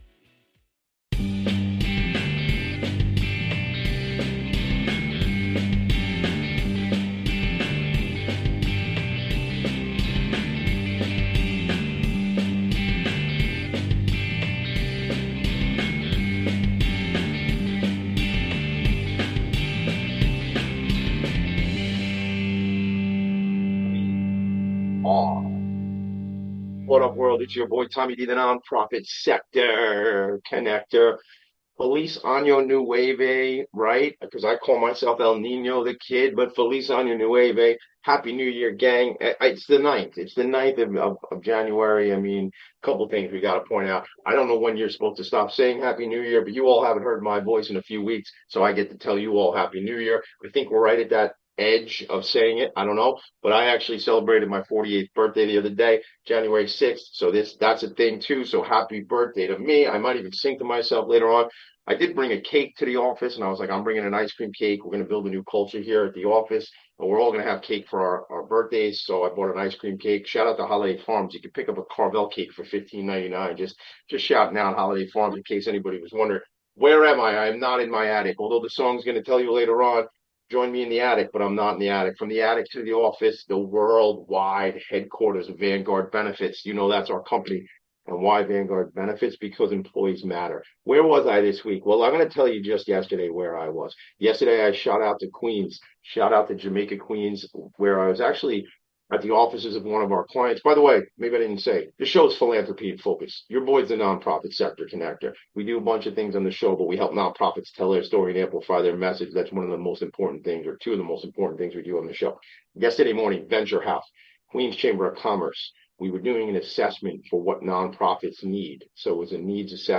What follows is a lively, heartfelt conversation about the power of the arts to change moods, open minds, and build community.